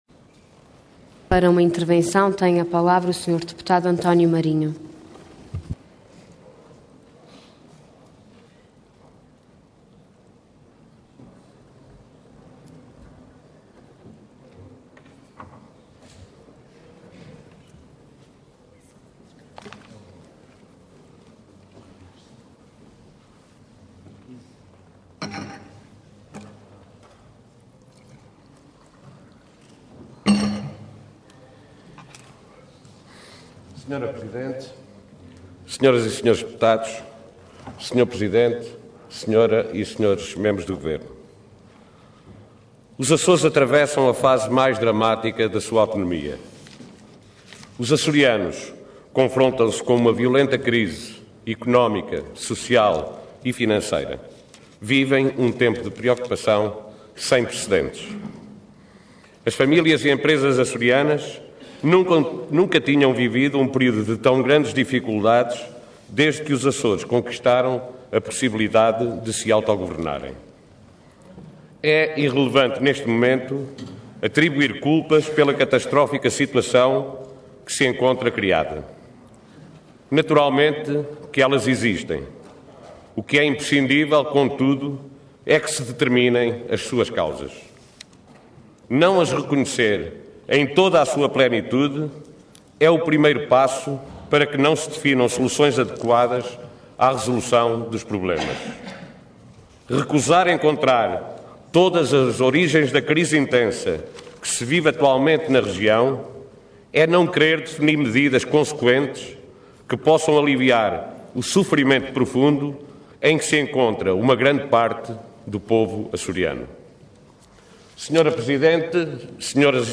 Intervenção Intervenção de Tribuna Orador António Marinho Cargo Deputado Entidade PSD